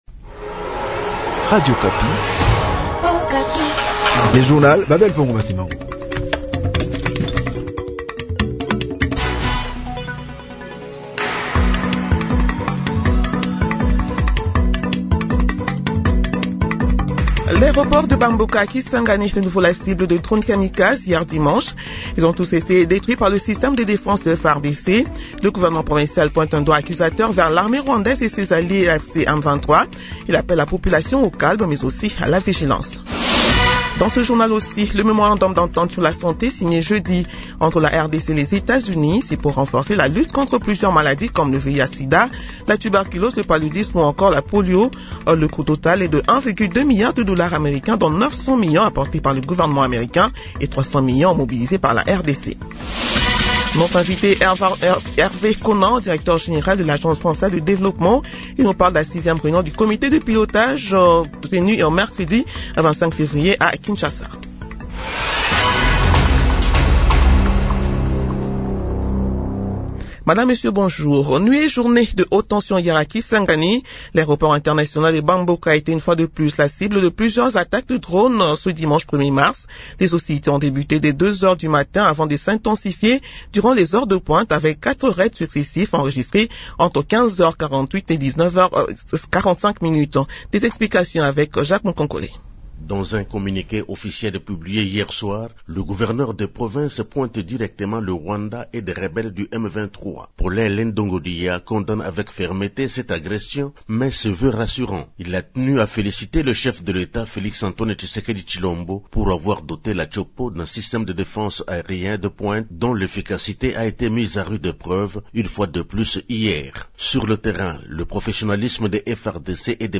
Journal matin 7 heures